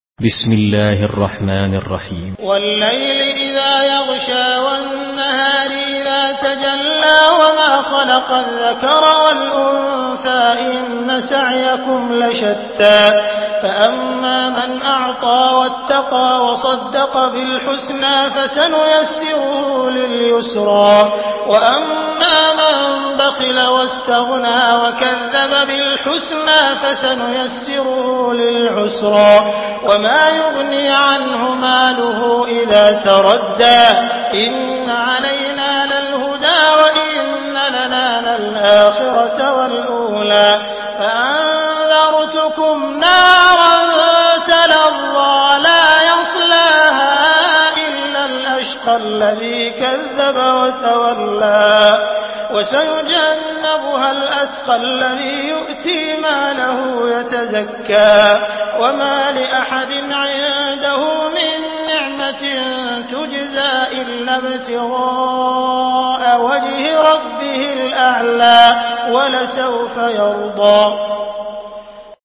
Surah Al-Layl MP3 in the Voice of Abdul Rahman Al Sudais in Hafs Narration
Murattal Hafs An Asim